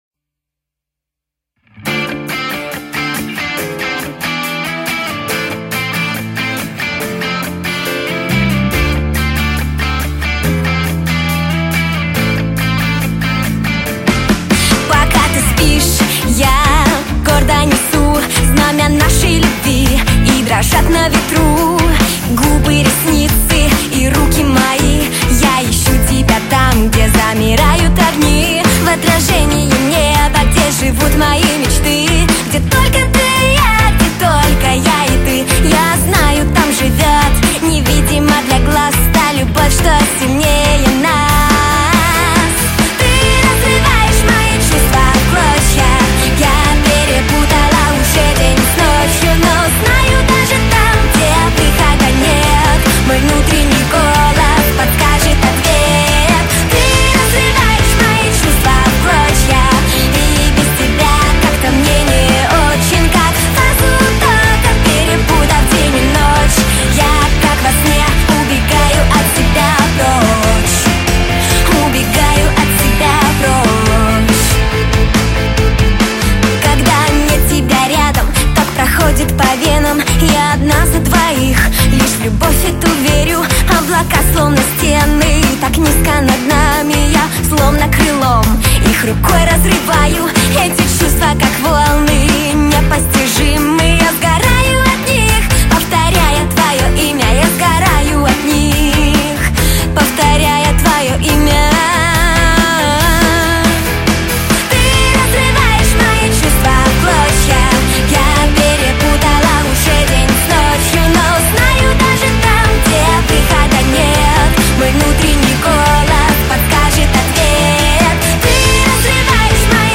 Сопрано
Спеціальність: эстрадно-джазовый вокал